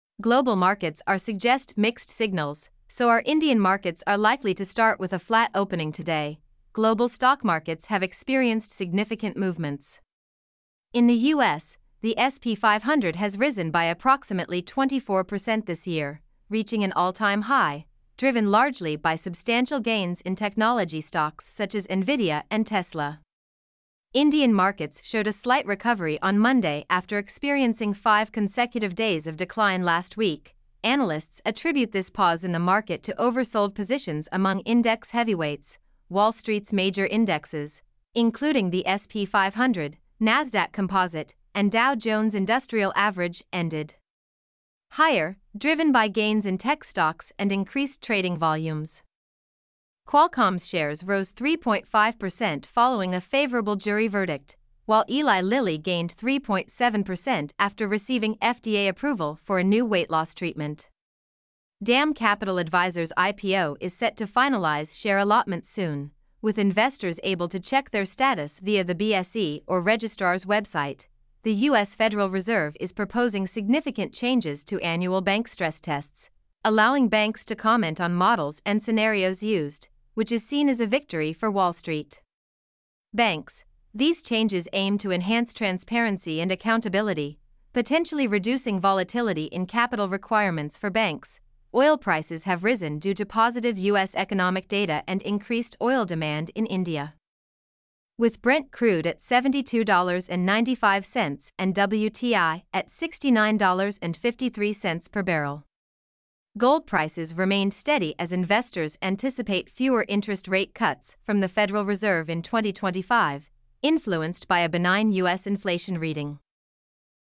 mp3-output-ttsfreedotcom-37.mp3